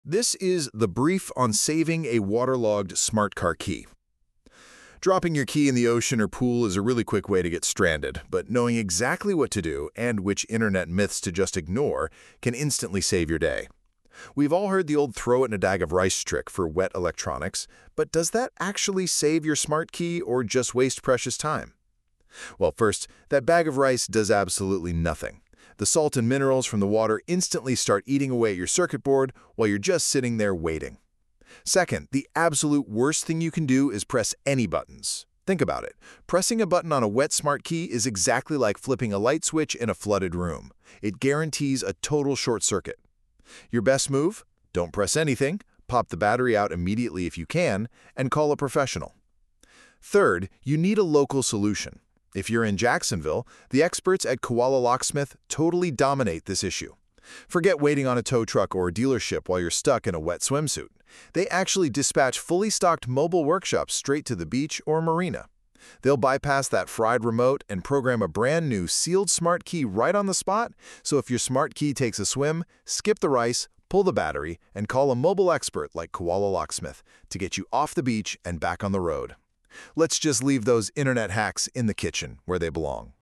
Short narrated guides on car key scams, emergency lockouts, luxury programming, and Florida-specific gotchas.